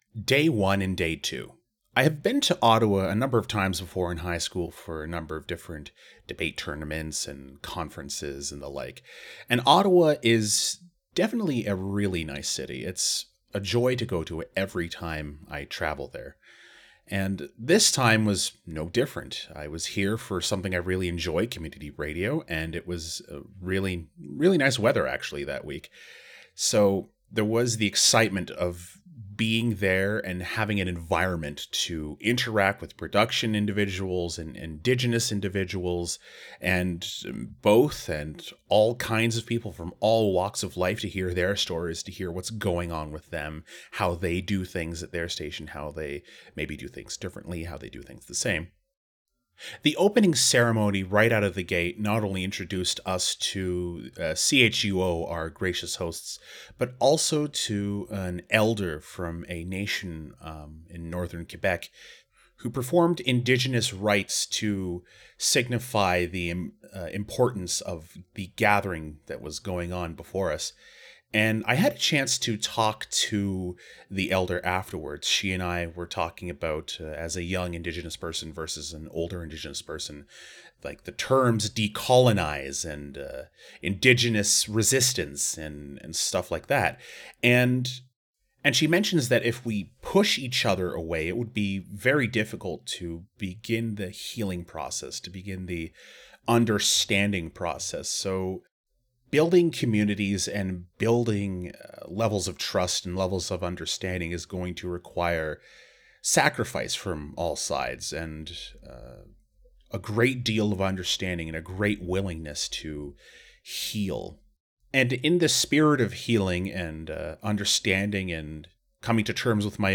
Type: Commentary
320kbps Stereo